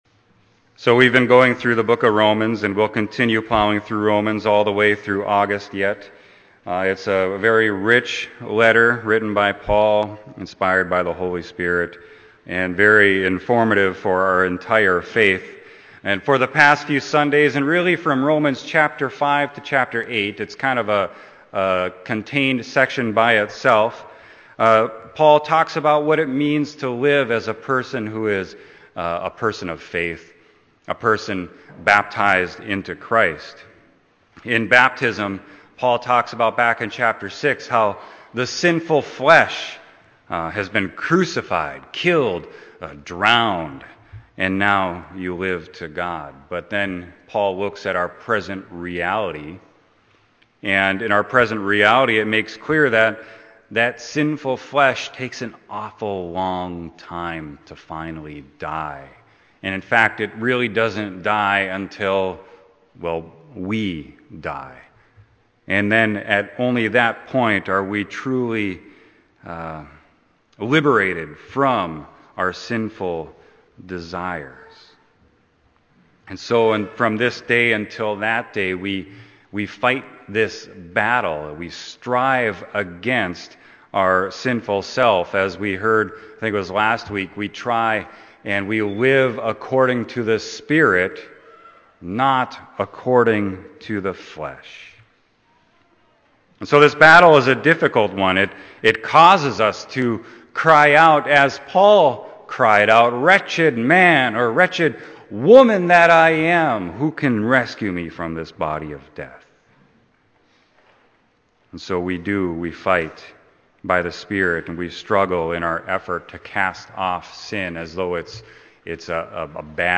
Sermon: Romans 8.12-25